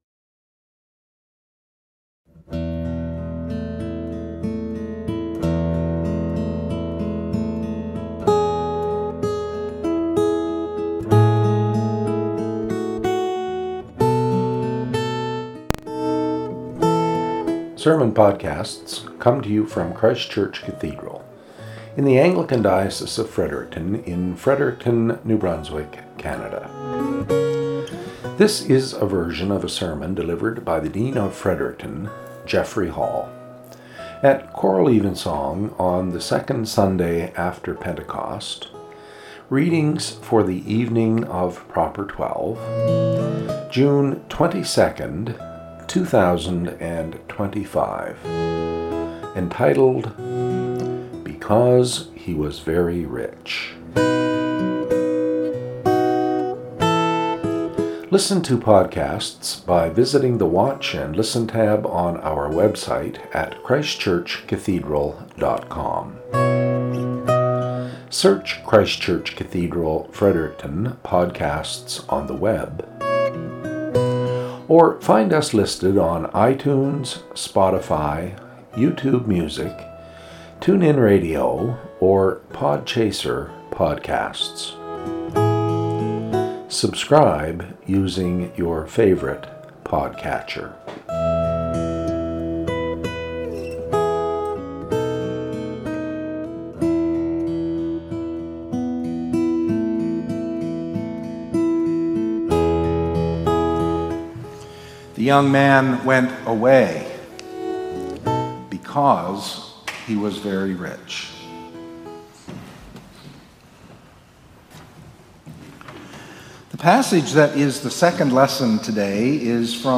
SERMON - "Because He Was Very Rich"
Lections for Evensong (Proper 12 Year 1) Numbers 14:26-45; Matthew 19:23-30